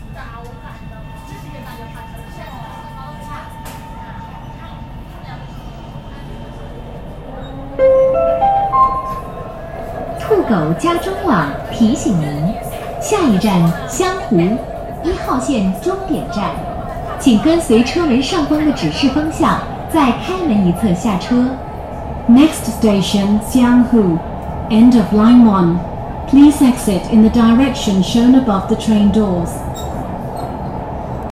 杭州地铁一号线湘湖站进站预报.ogg